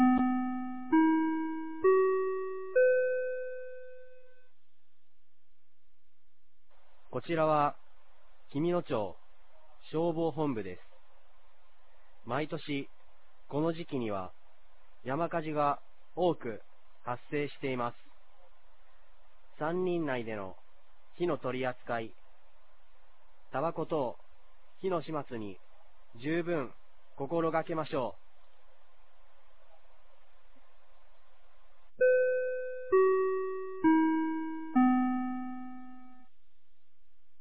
2021年05月15日 16時00分に、紀美野町より全地区へ放送がありました。